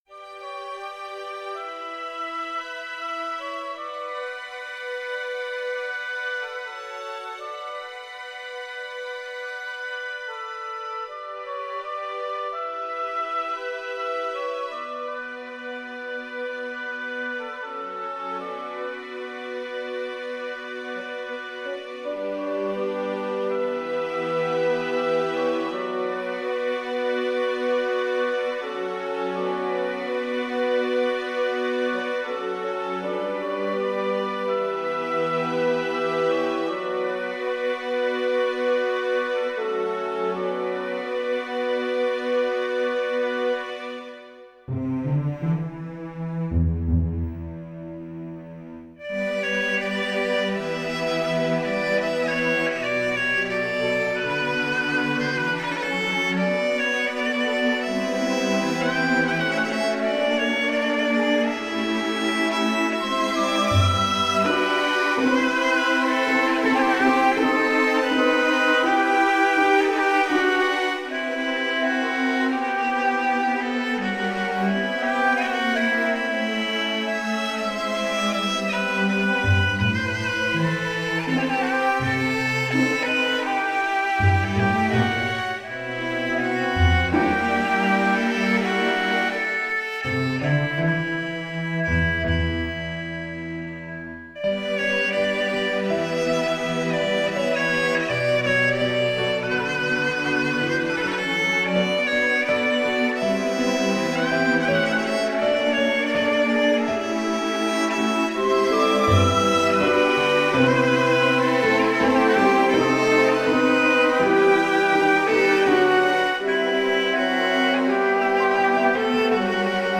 Genre: Oriental, Ethnic, World, Duduk, New Age
guitar
kamancha